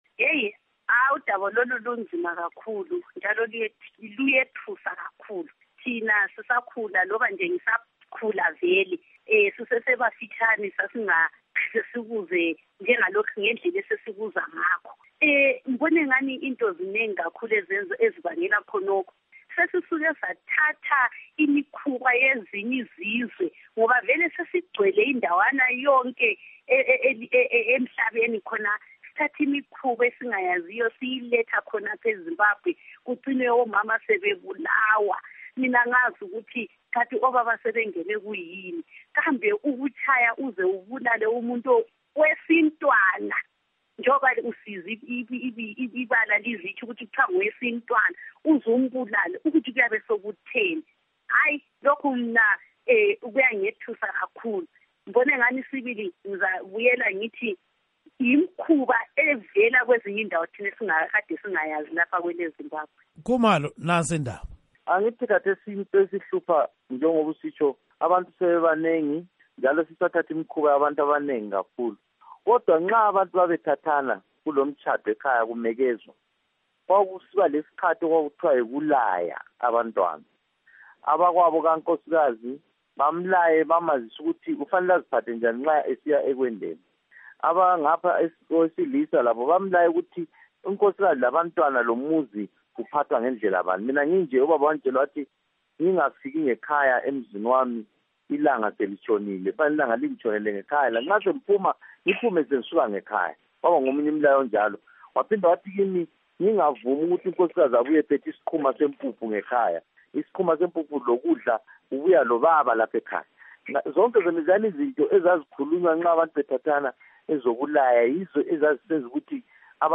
IngxoxoEsiyenze